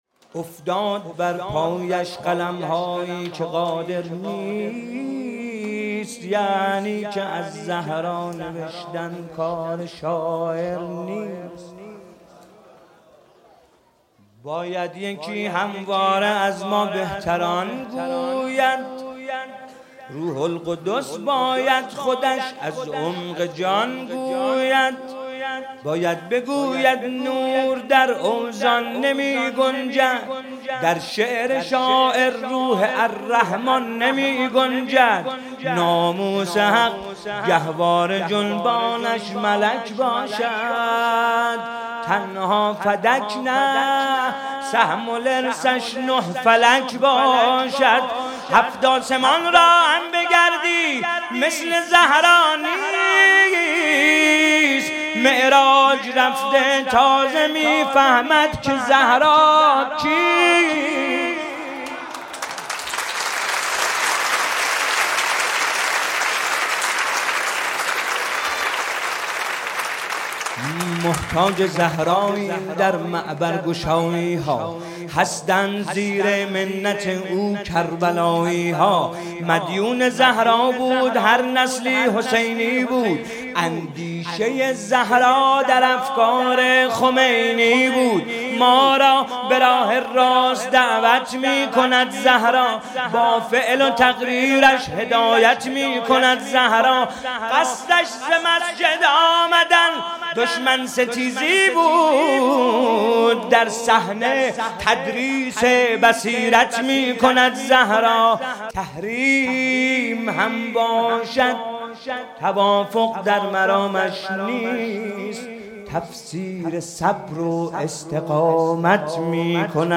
ولادت حضرت زهرا سلام‌الله‌علیها